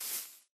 step / grass6